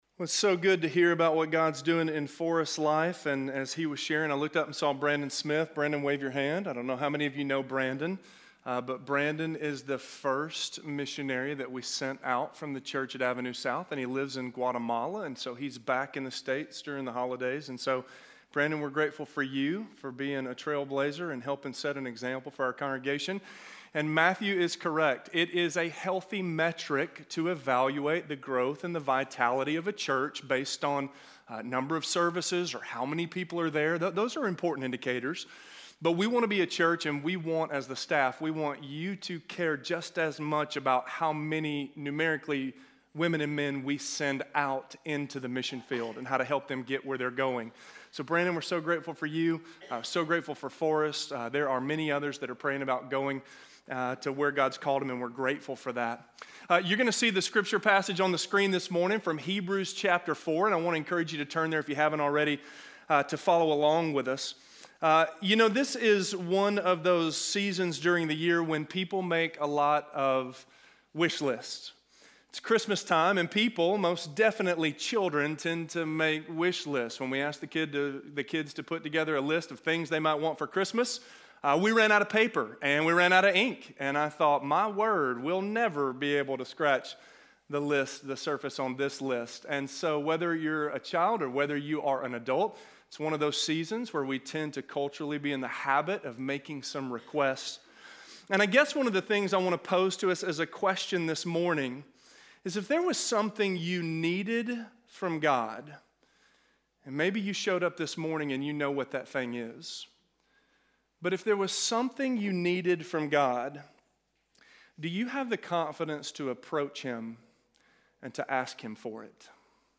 We Can Know...Confidence - Sermon - Avenue South